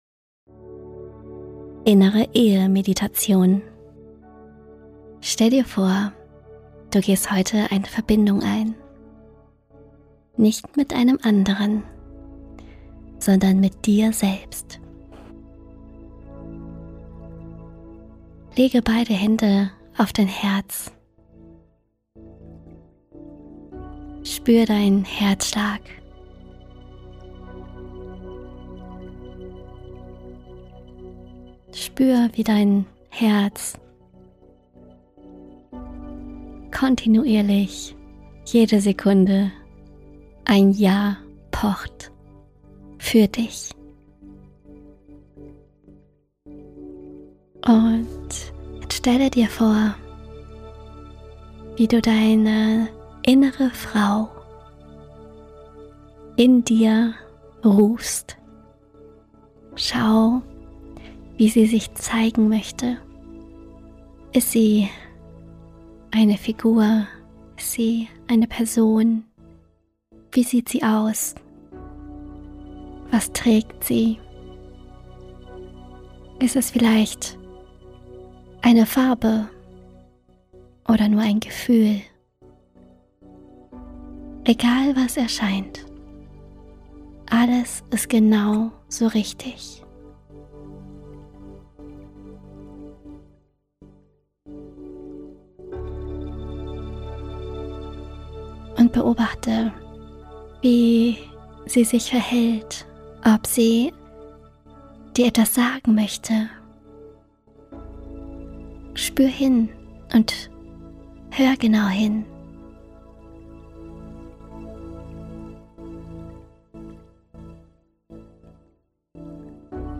Integrationsmeditation zur Bonusfolge